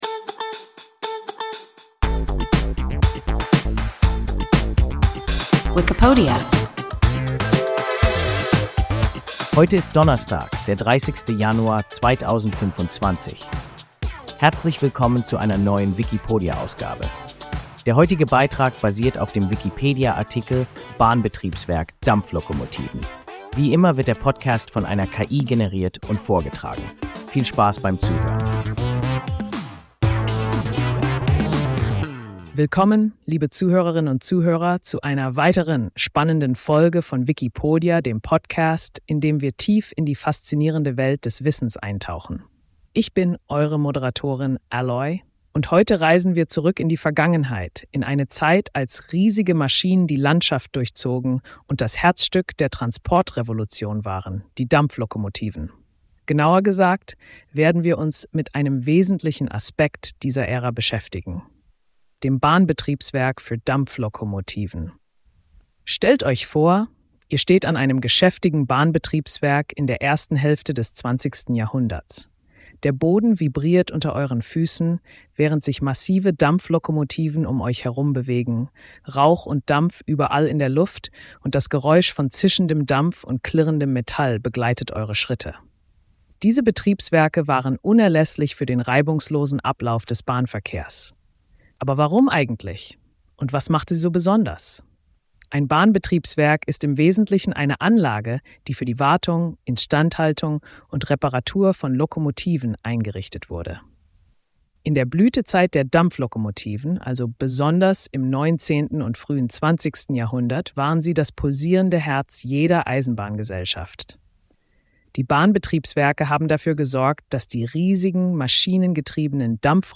Bahnbetriebswerk (Dampflokomotiven) – WIKIPODIA – ein KI Podcast